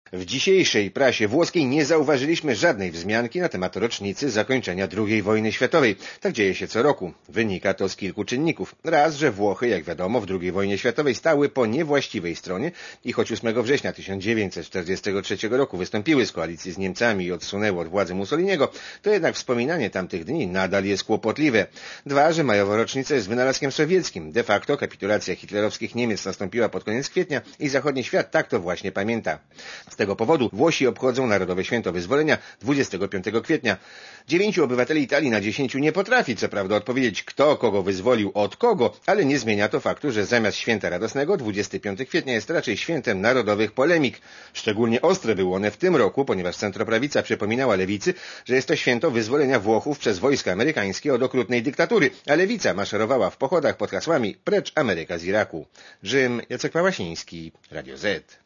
Korespondencja z Rzymu (230Kb)